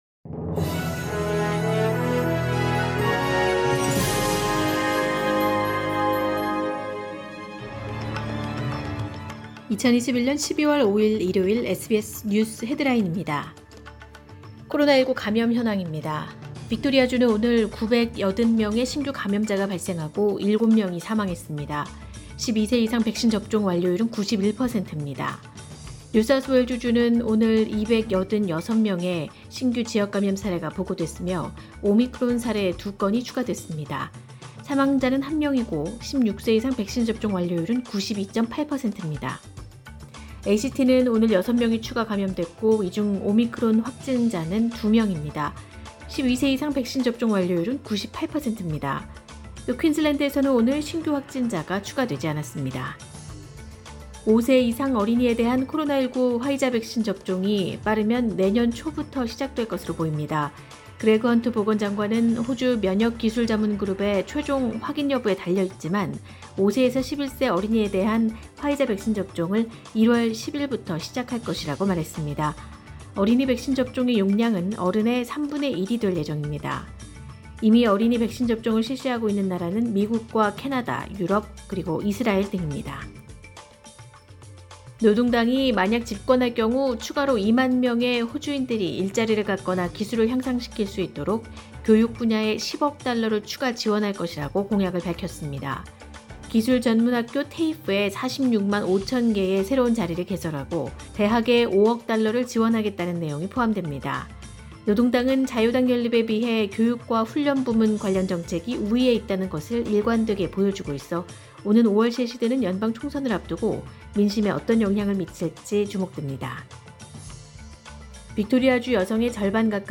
2021년 12월 5일 일요일 SBS 뉴스 헤드라인입니다.